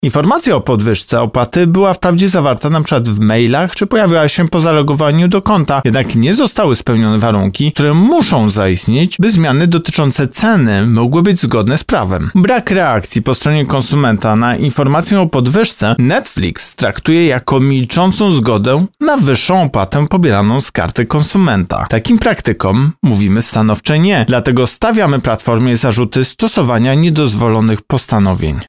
-mówi Tomasz Chróstny, prezes Urzędu Ochrony Konkurencji i Konsumentów.